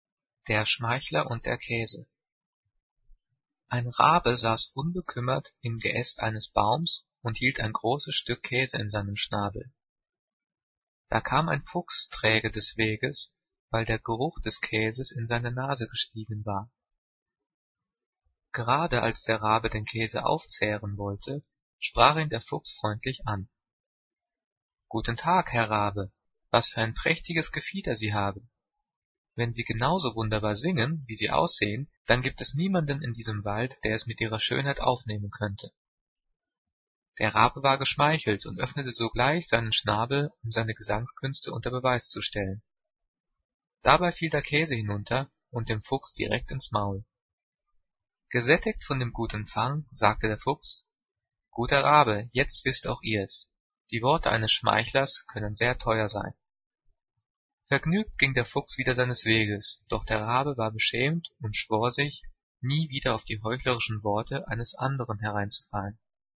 Gelesen:
gelesen-der-schmeichler-und-der-kaese.mp3